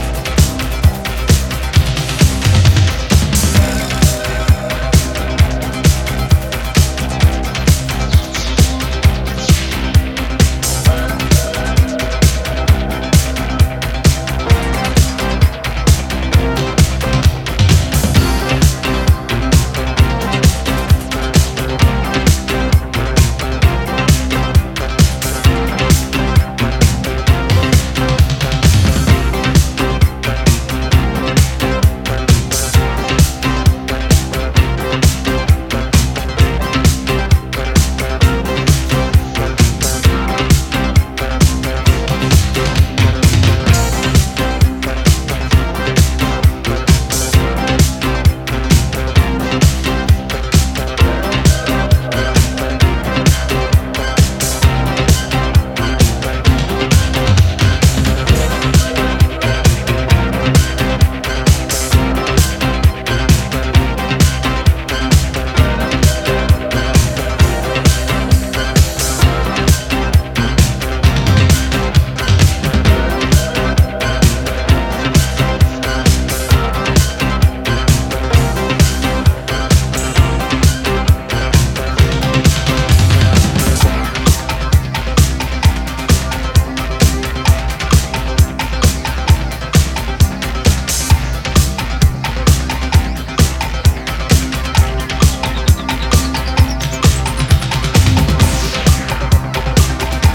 インストのコズミック・ハイエナジー
ツボを抑えたエクステンドの前者、原曲を引き立てながらトリッピーな仕掛けを施した後者、いずれもナイス！